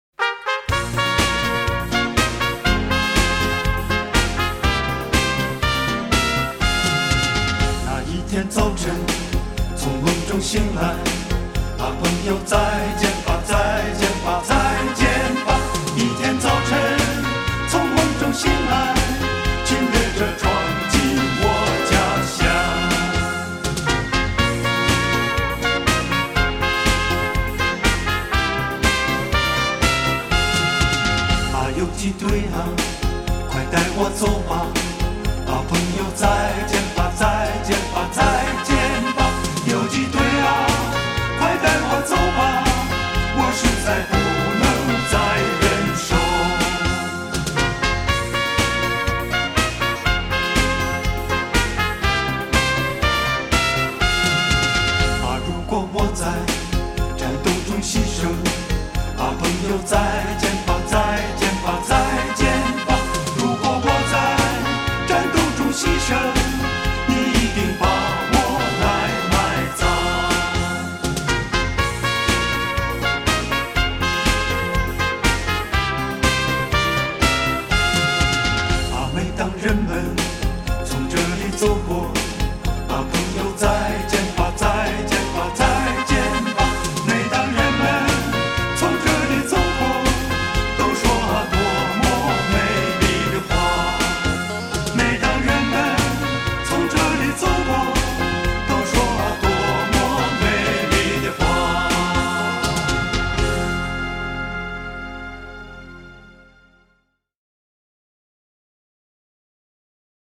录音上佳